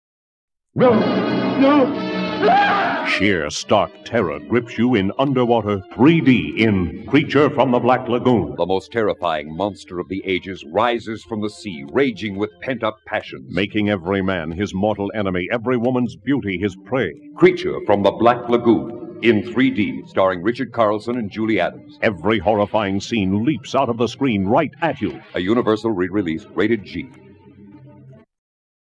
1972 3D Radio Spots